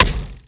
arrow_hit2.wav